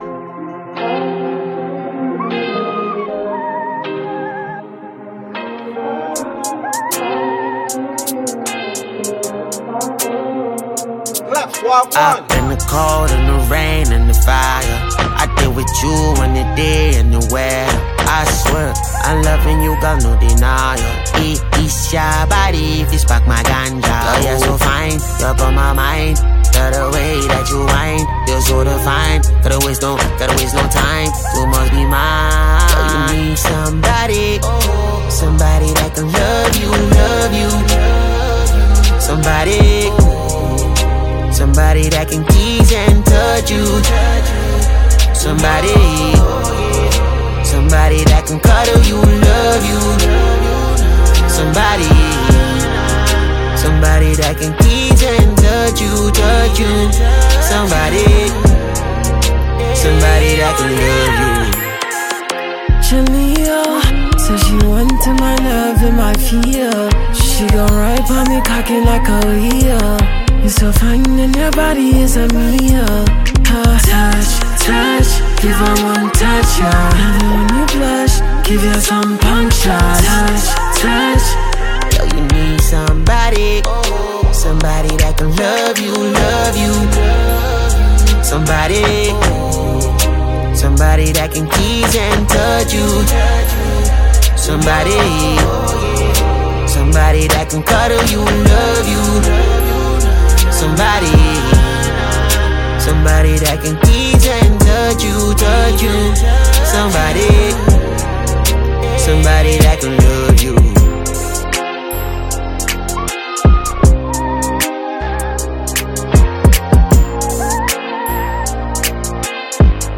particularly for fans of Afrobeat and contemporary sounds.
infectious beats